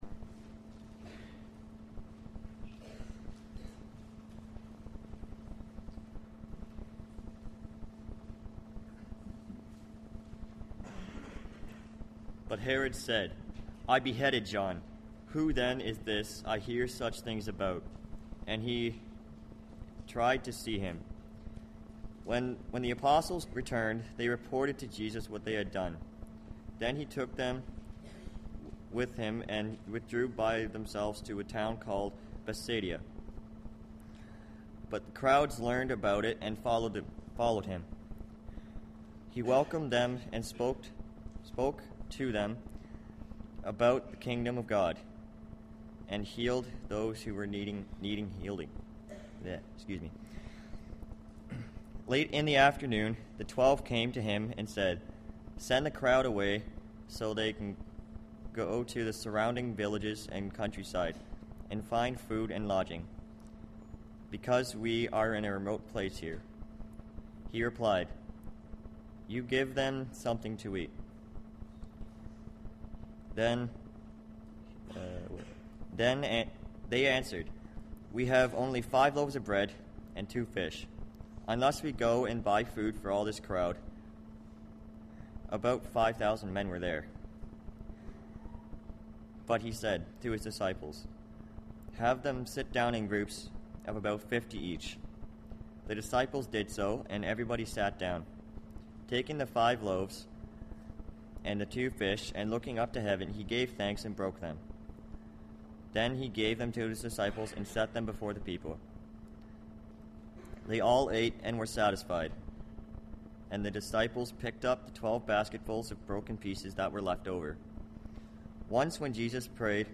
Dundas hosted the Classis Youth Service. We will study Luke 9:18-27 to understand what Jesus demands from the world and why people don't like him because of it.